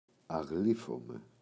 αγλείφομαι [aꞋγlifome]